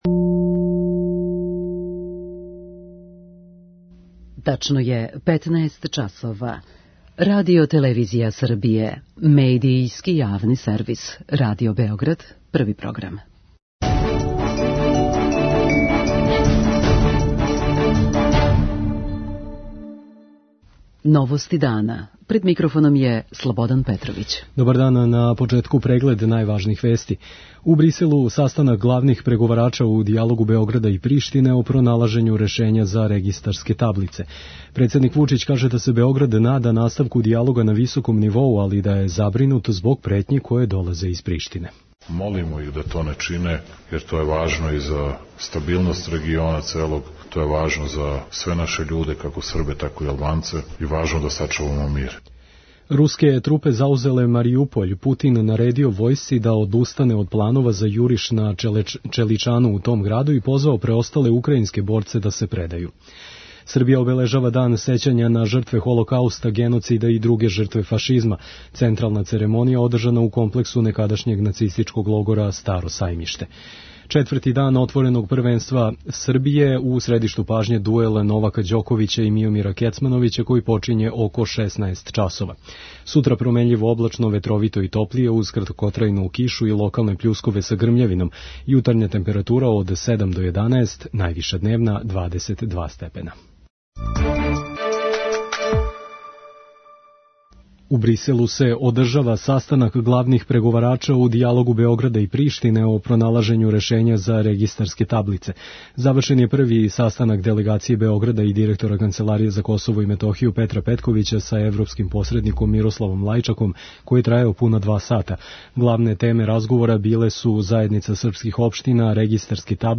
Украјинске власти наводе и да је у току руско гранатирање Харкова. преузми : 6.50 MB Новости дана Autor: Радио Београд 1 “Новости дана”, централна информативна емисија Првог програма Радио Београда емитује се од јесени 1958. године.